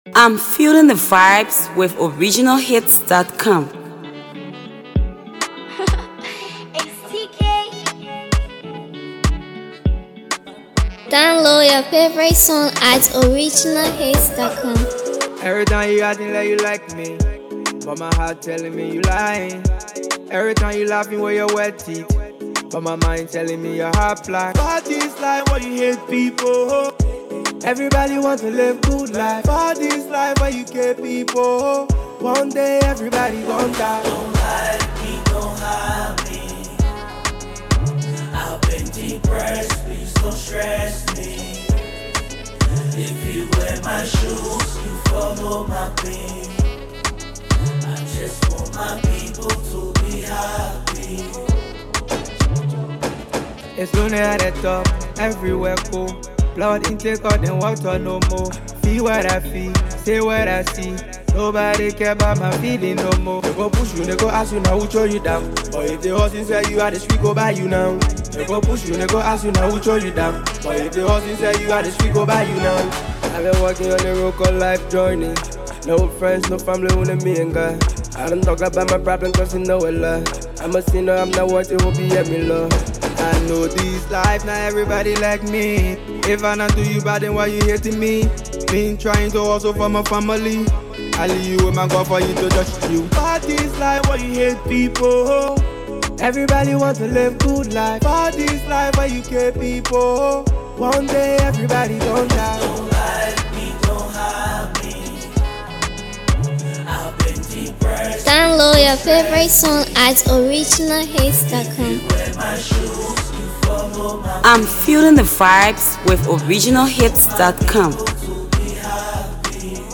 studio banger
heartfelt banger
It’s a powerful piece.